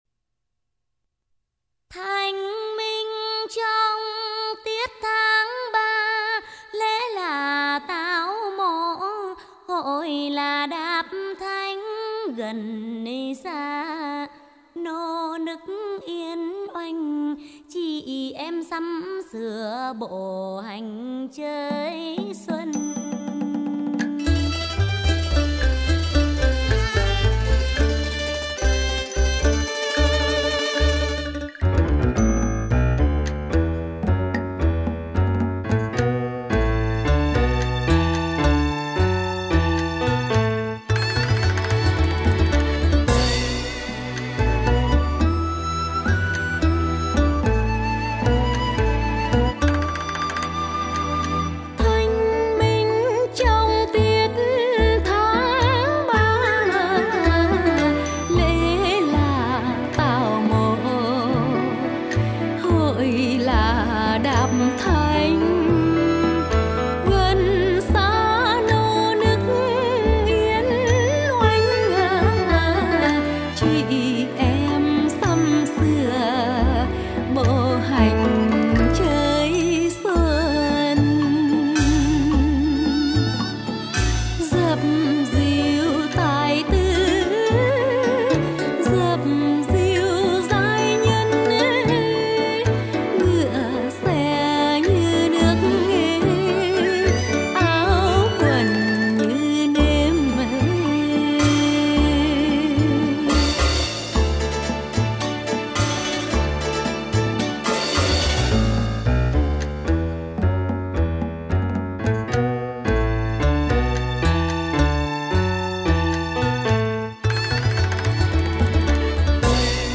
Nhạc trữ tình, vui tươi, hứng khởi.